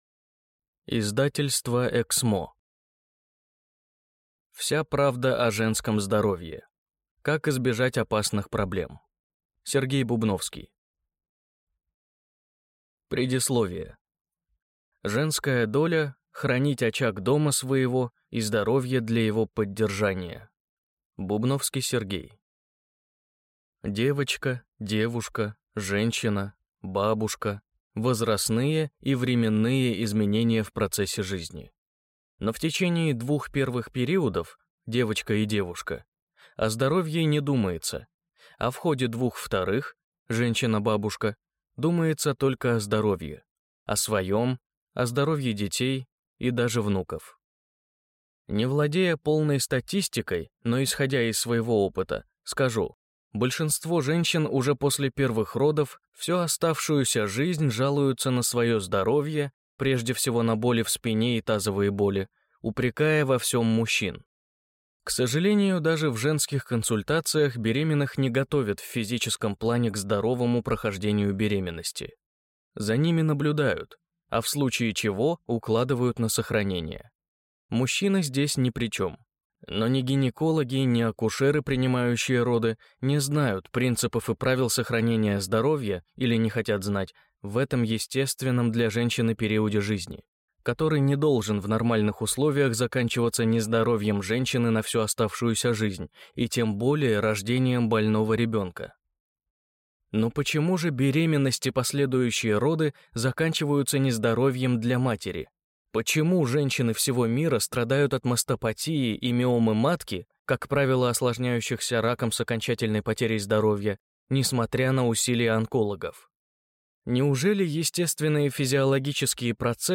Аудиокнига Вся правда о женском здоровье. Как избежать опасных проблем | Библиотека аудиокниг